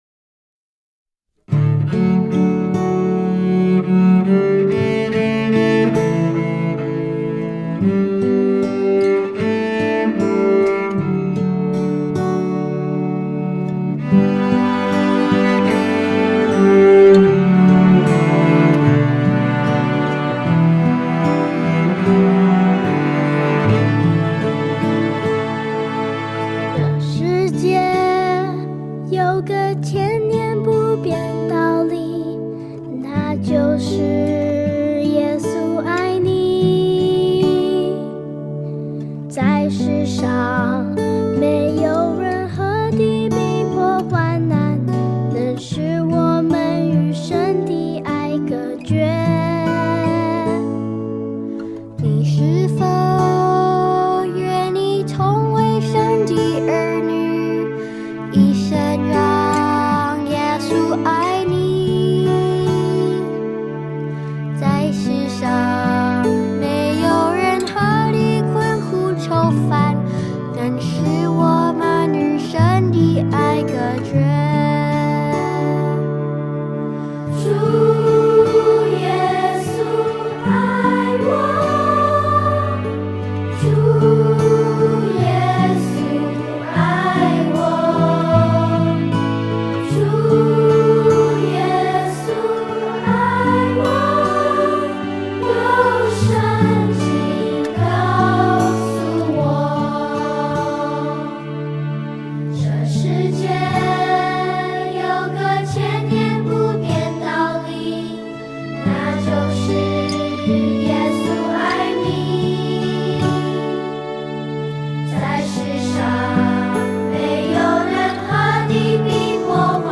跟唱建议 动作跟唱，有视频和音频两部分。